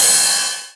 Index of /90_sSampleCDs/Club_Techno/Percussion/Cymbal
Ride_01.wav